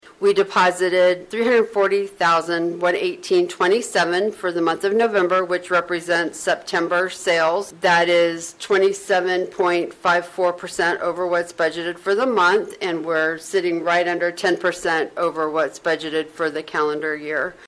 Treasurer Shilo Heger said they ended the month for the county sales tax fund in a good place.